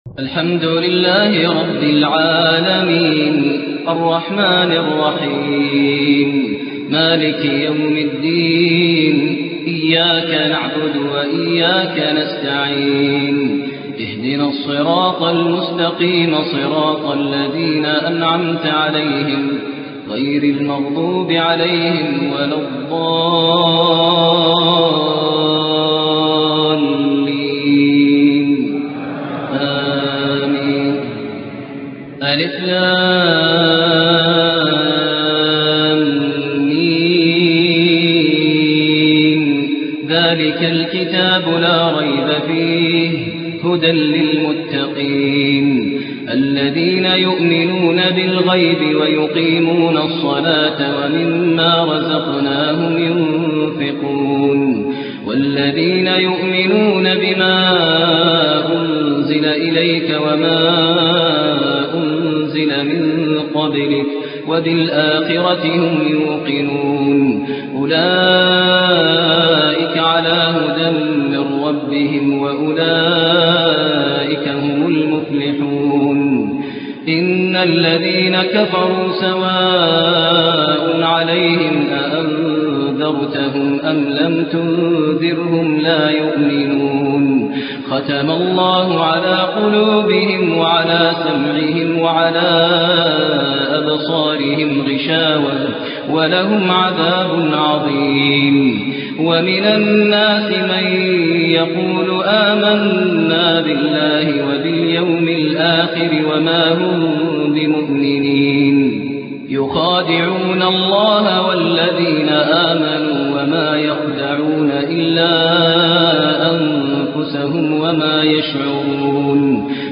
تهجد ليلة 21 رمضان 1427هـ من سورة البقرة (1-74) Tahajjud 21 st night Ramadan 1427H from Surah Al-Baqara > تراويح الحرم النبوي عام 1427 🕌 > التراويح - تلاوات الحرمين